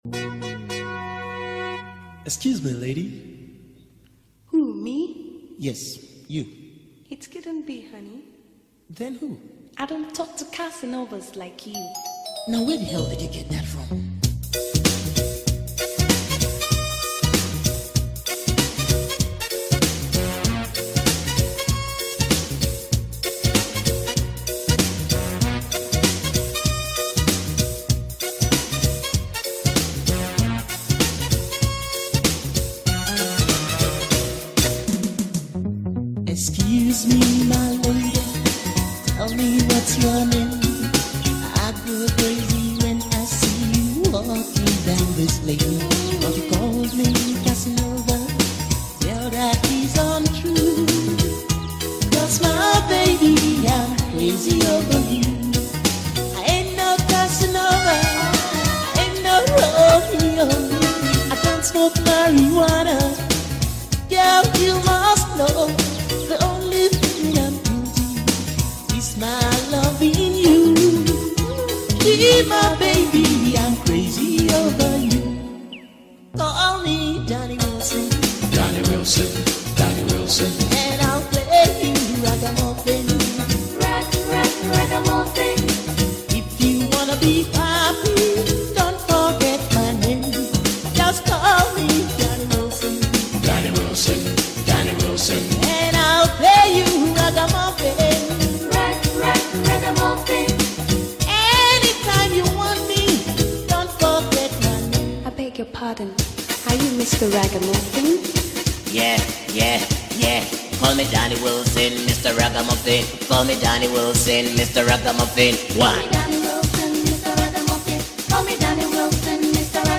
Afro-pop and dancehall